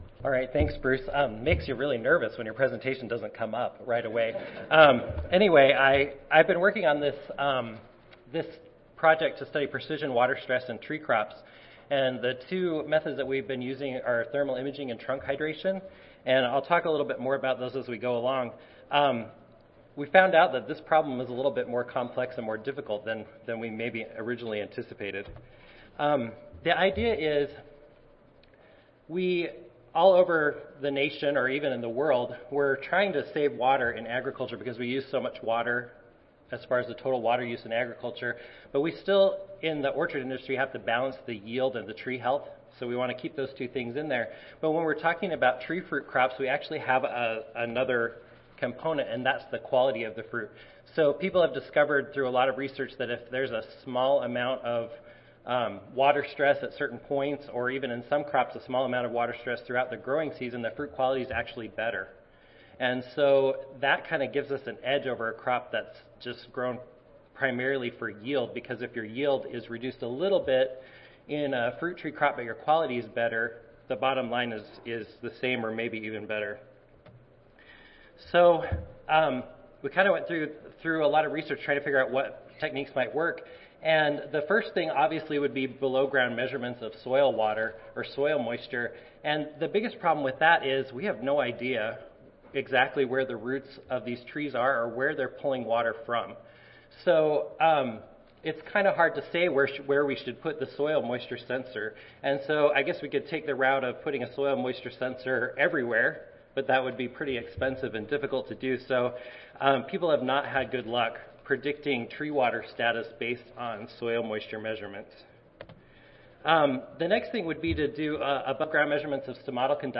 Utah State University Audio File Recorded Presentation